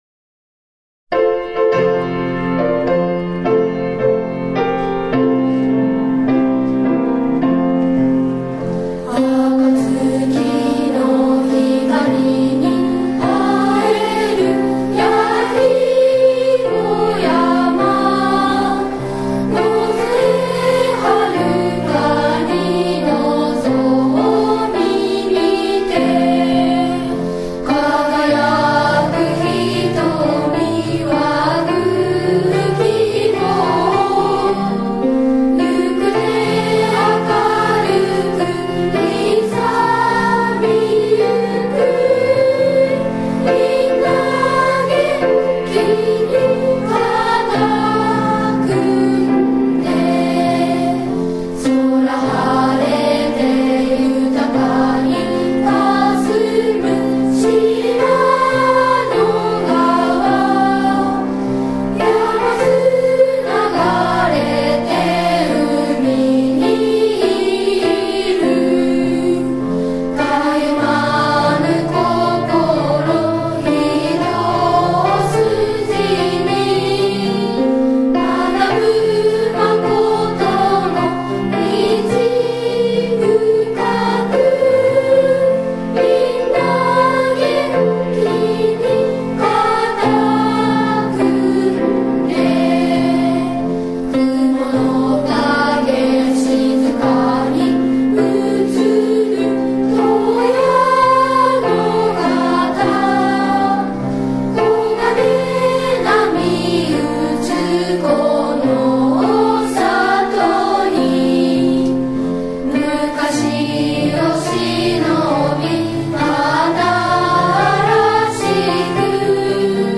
◆校歌